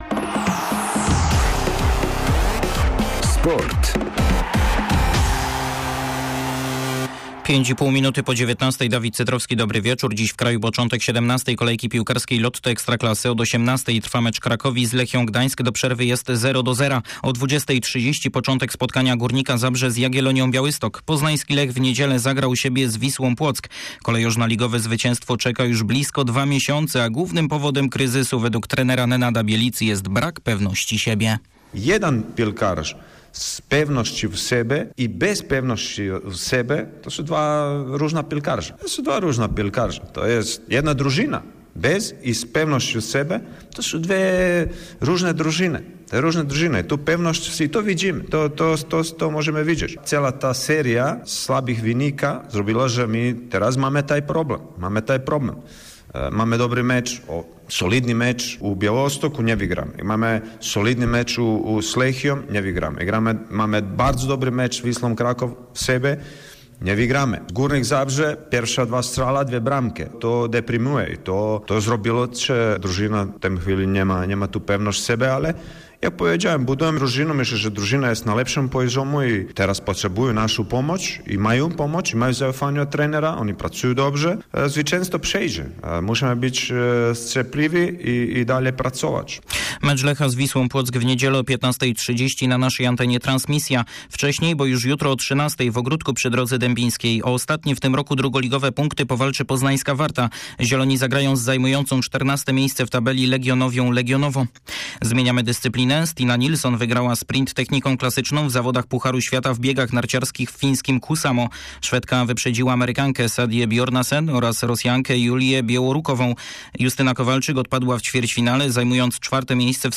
24.11 serwis sportowy godz. 19:05